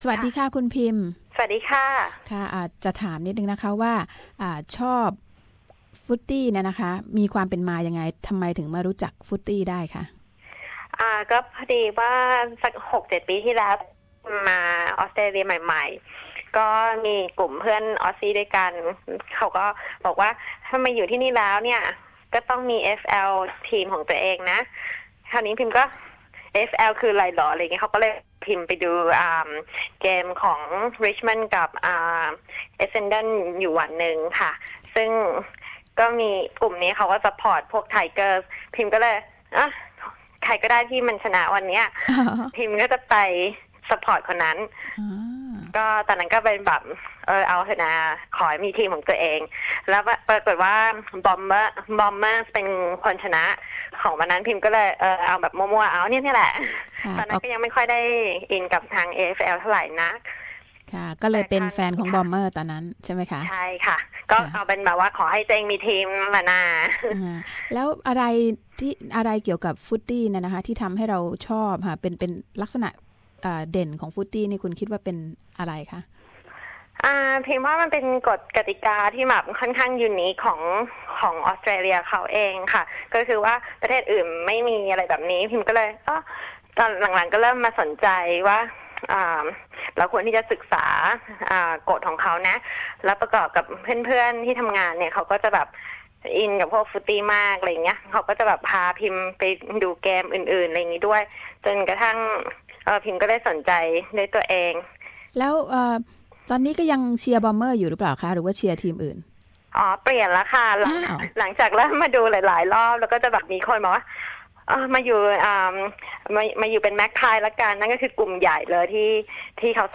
ฤดูกาลแข่งขันฟุตบอล Australian Rule หรือ Footy ก็กลับมาอีกครั้งแล้ว SBS ภาคภาษาไทยจึงไปคุยกับสามสาวไทยที่สนใจ Footy ว่ากีฬาที่มีเอกลักษณ์ของออสเตรเลียชนิดนี้เล่นกันอย่างไร และทำไมคนถึงคลั่งไคล้กันนัก